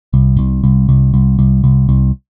FINGER縺ｯ謖繧剃ｽｿ縺｣縺ｦ貍泌･上☆繧句･乗ｳ輔〒縺吶よ沐繧峨°縺乗ｸｩ縺九∩縺ｮ縺ゅｋ髻ｳ濶ｲ縺檎音蠕ｴ縺ｧ縲√リ繝√Η繝ｩ繝ｫ縺ｧ貊代ｉ縺九↑繝医ｼ繝ｳ繧貞刈縺医◆縺縺ｨ縺阪↓菴ｿ縺縺ｾ縺吶